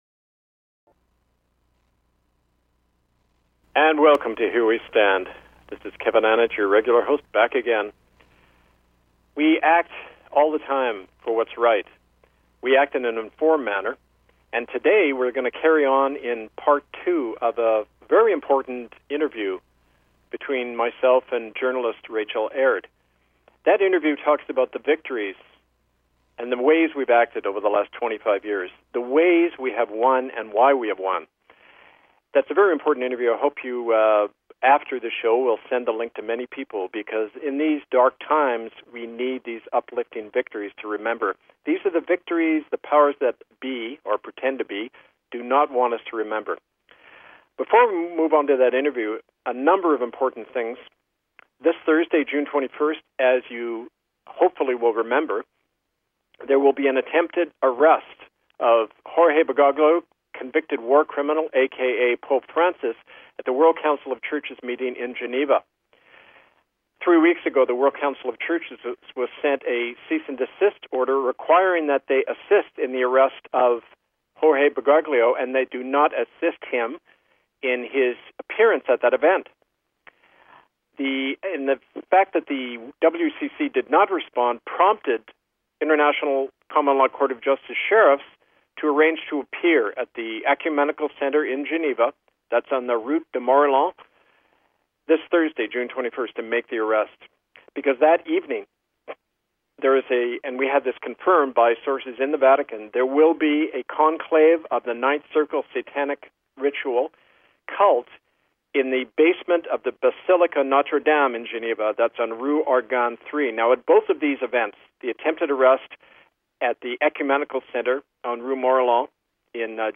Talk Show Episode
Rerun from Radio Free Kanata July 23, 2017